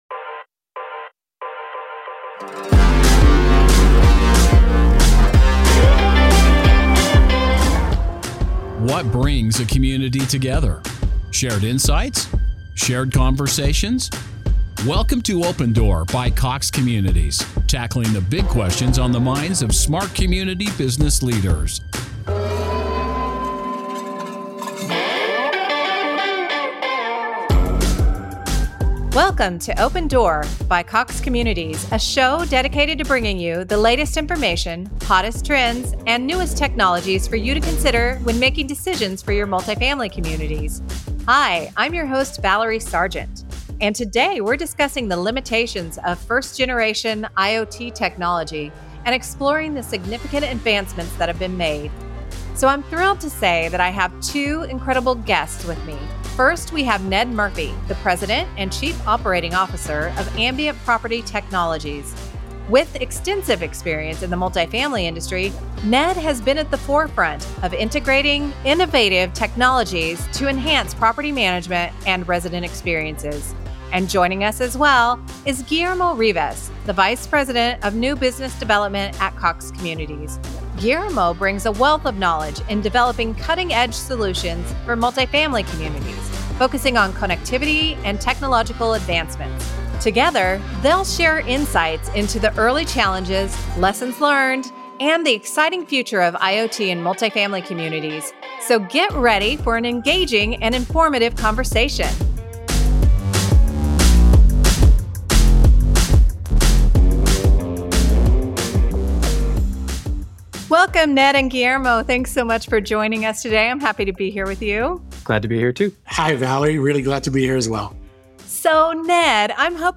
as she has conversations with thought leaders and experts to help you make the best decisions for your communities.